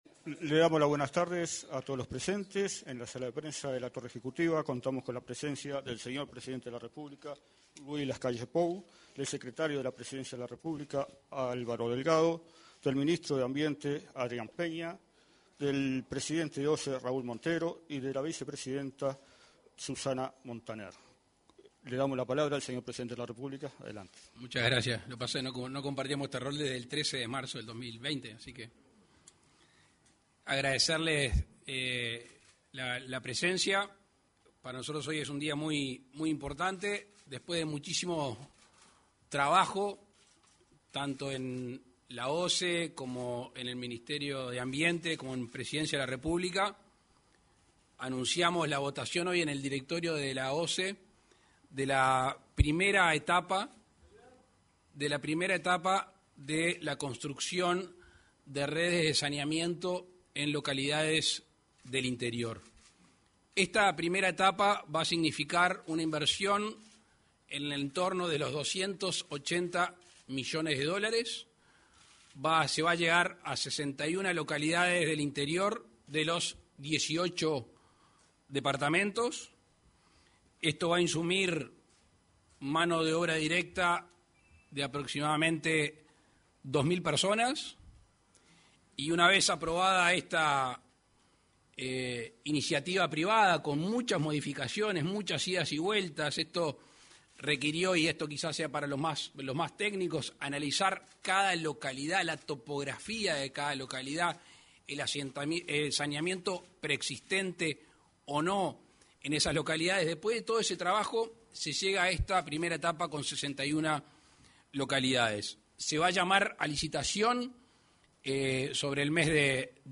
Presidente Lacalle Pou informó sobre saneamiento en conferencia de prensa
Presidente Lacalle Pou informó sobre saneamiento en conferencia de prensa 28/12/2022 Compartir Facebook X Copiar enlace WhatsApp LinkedIn Este 28 de diciembre, en la sala de prensa de Torre Ejecutiva, el presidente de la República, Luis Lacalle Pou, acompañado por el secretario de Presidencia, Álvaro Delgado; el ministro de Ambiente, Adrián Peña; el presidente de OSE, Raúl Montero, y la vicepresidenta de esa empresa, Susana Montaner, se expresaron en conferencia de prensa.